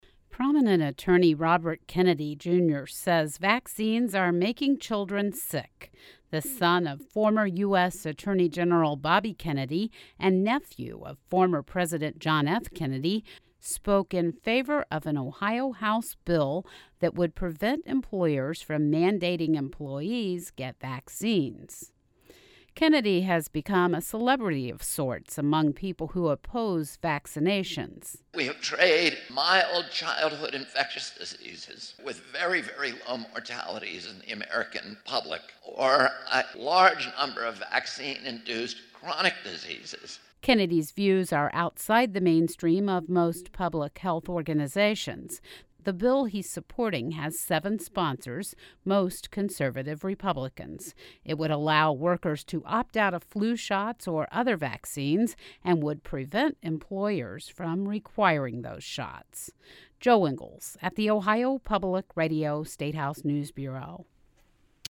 Prominent attorney Robert Kennedy Jr says vaccines are making children sick. The son of former U.S. Attorney General Bobby Kennedy and nephew of former President John F Kennedy spoke at the Ohio Statehouse in favor of a bill that would prohibit employers from mandating employees get vaccines.